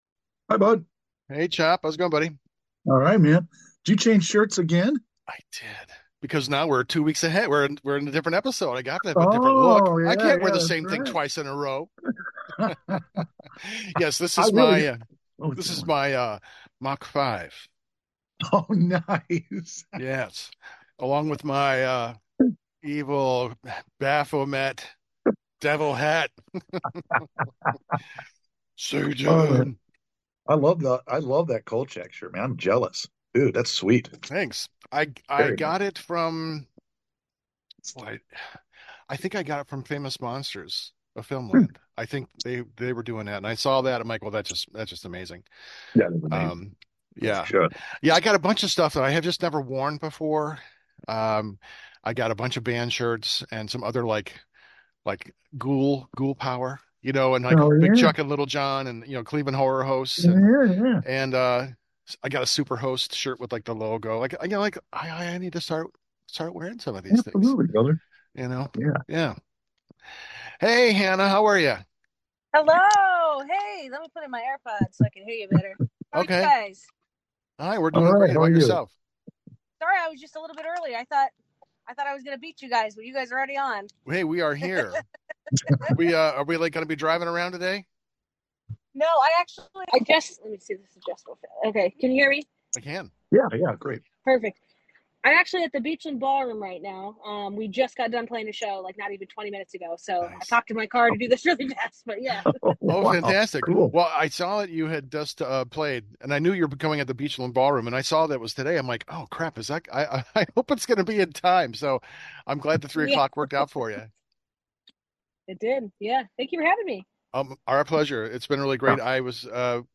stops by for a lively interview and talk about all things Iron Bitch, the Cleveland metal scene, and all of the games you love!